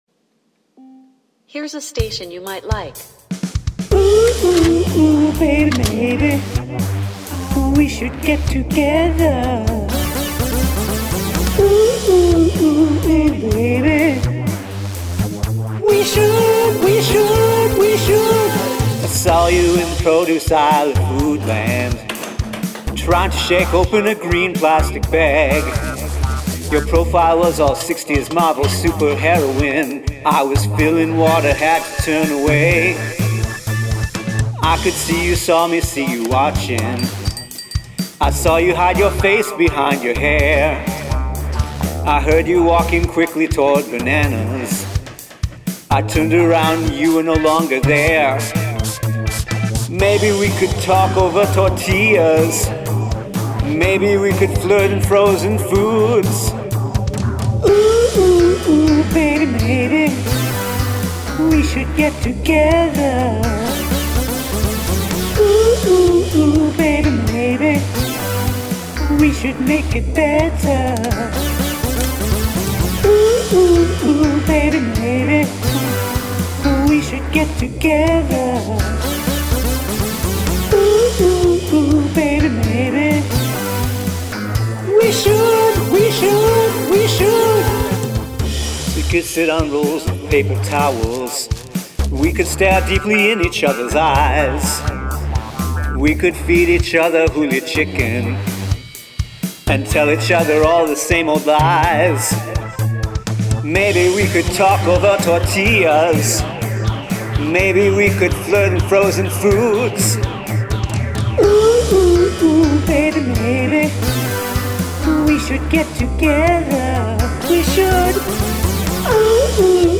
Have a guest play a household item on the track
Mix wise, those synths are so much louder than the drums.